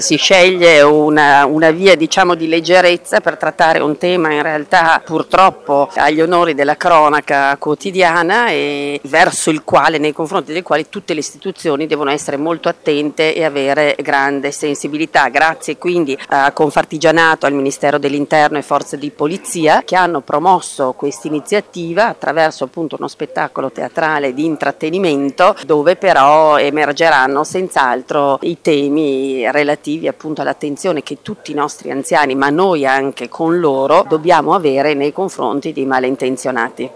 Assessore alla Cultura, Turismo, Manifestazioni, Pari opportunità, Francesca Briani:
Assessore-alla-cultura-del-comune-di-Verona-Francesca-Briani.mp3